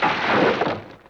Index of /90_sSampleCDs/E-MU Producer Series Vol. 3 – Hollywood Sound Effects/Water/Falling Branches
LIMB CRAC06L.wav